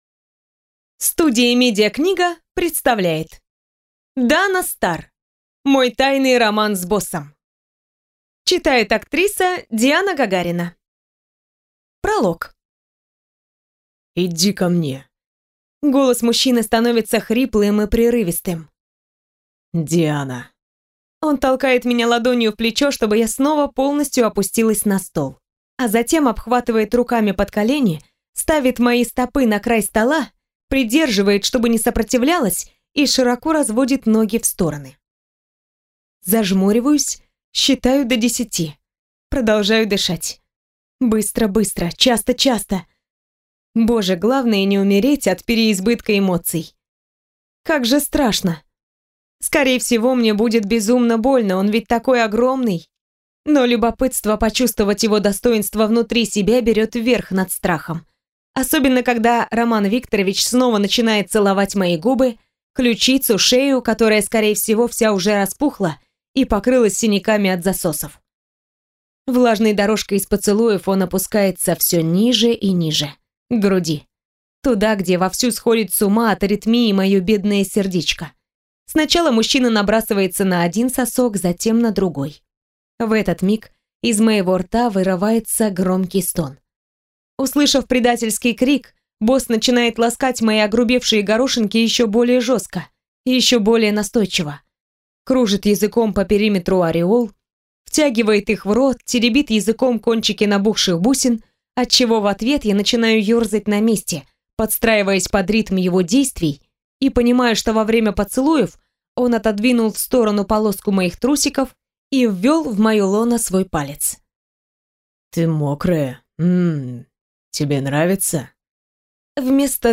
Аудиокнига Мой тайный роман с боссом | Библиотека аудиокниг